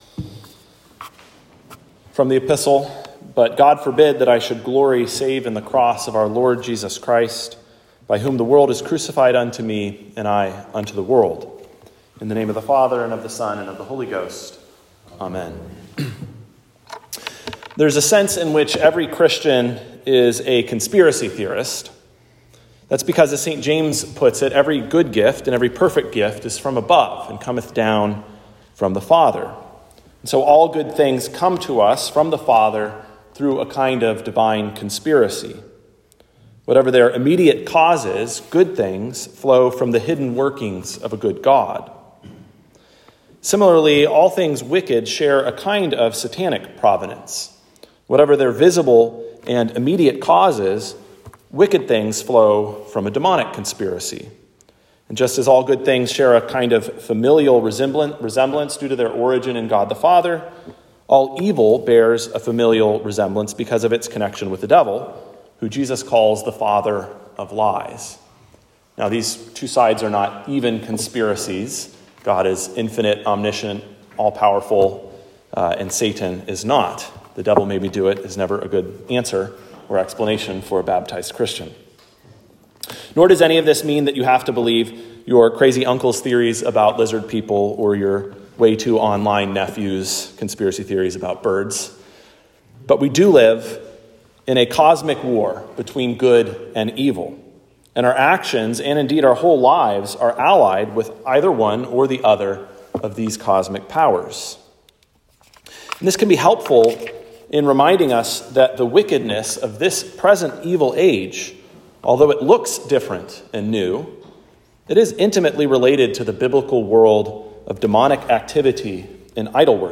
Sermon for Trinity 15